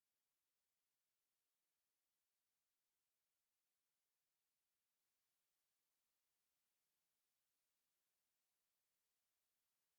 spk_cal_silence.wav